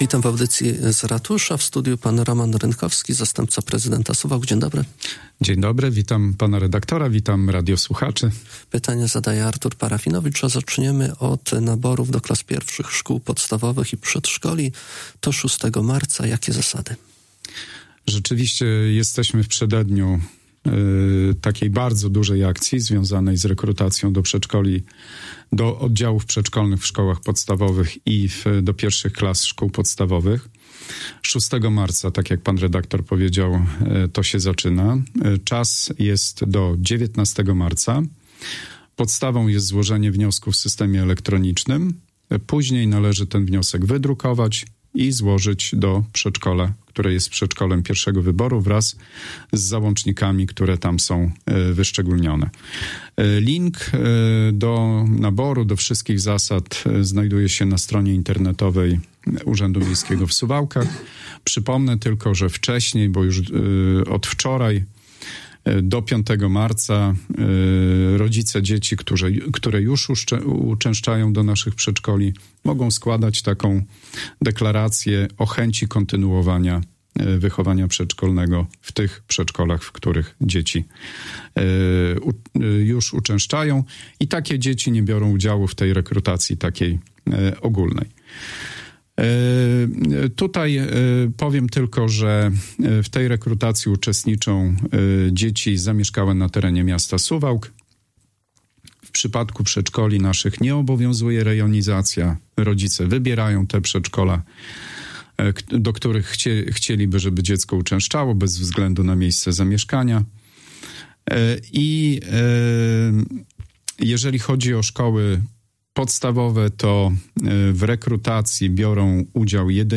Nabór do klas pierwszych szkół podstawowych i przedszkoli, działalność lodowiska i harmonogram realizacji Suwalskiego Budżetu Obywatelskiego 2026 – to między innymi tematy kolejnej edycji „Audycji z Ratusza”. Na pytania dotyczące spraw mieszkańców i miasta odpowiadał Roman Rynkowski, zastępca prezydenta Suwałk.